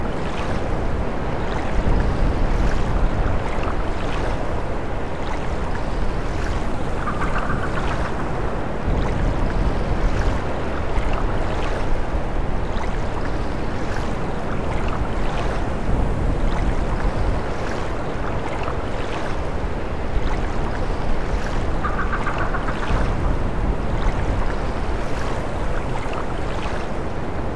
标签： misc cartoon animation soundtrack ost cute happy loop background music sad ukulele short
声道立体声